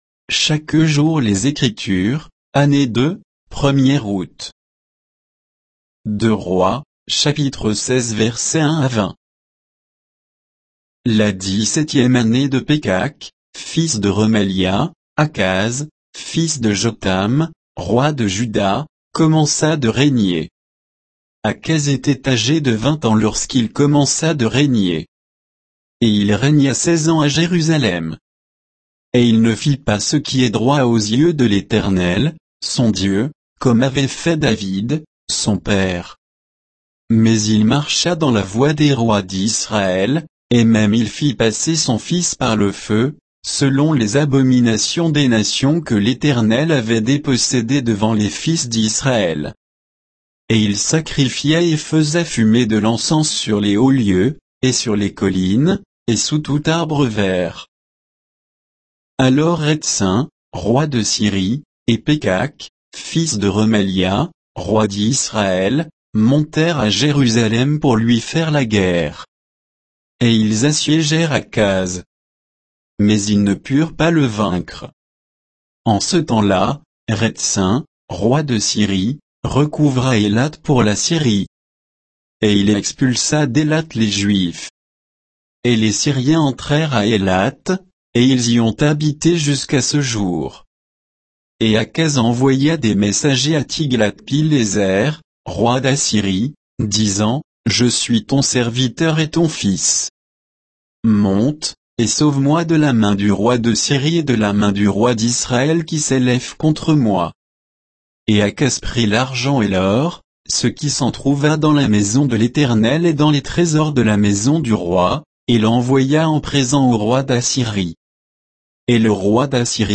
Méditation quoditienne de Chaque jour les Écritures sur 2 Rois 16, 1 à 20